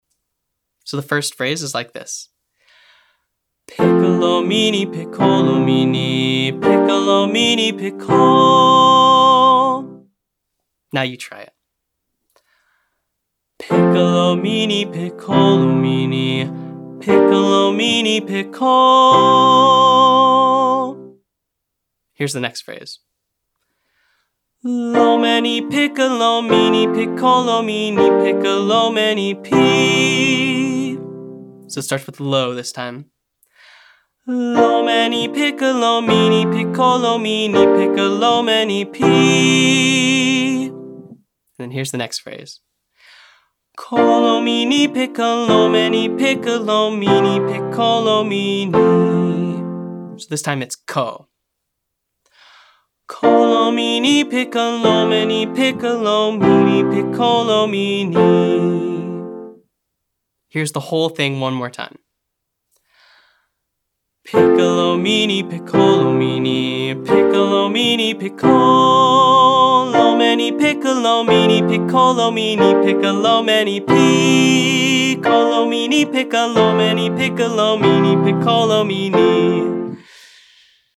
Singing Longer Phrases - Online Singing Lesson